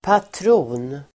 Uttal: [patr'o:n]